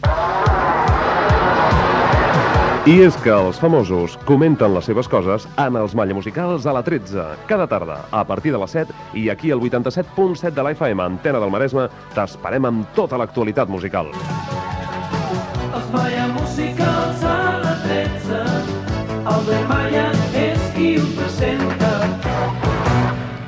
Promoció del programa, amb identificació de l'emissora.
Musical